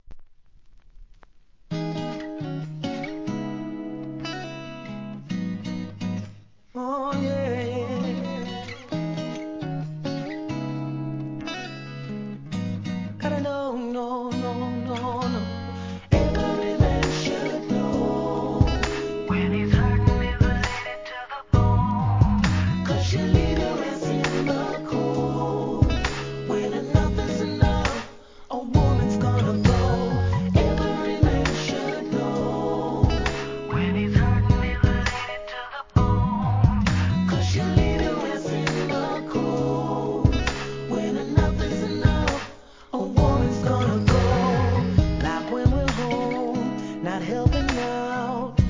REGGAE
アコースティックのアーバンで哀愁漂うミディアム